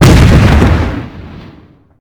grenadeexplodenextroom.ogg